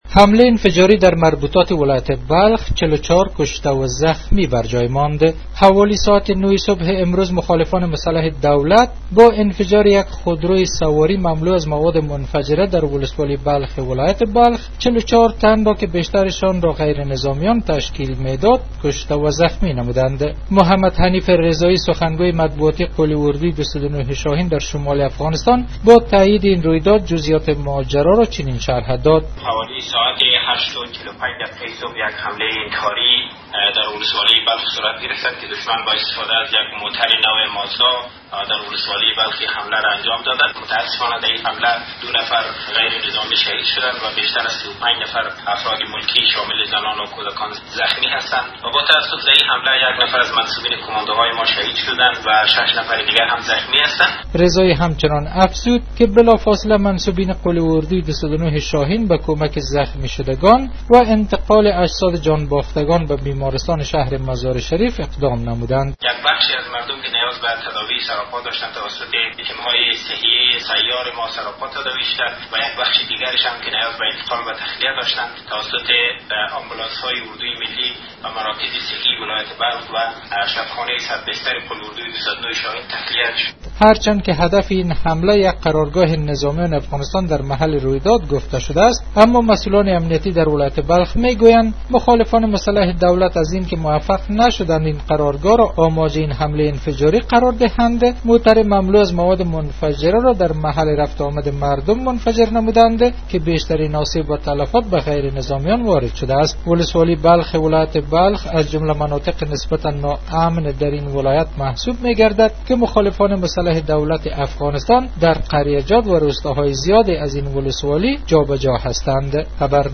جزئیات بیشتر در گزارش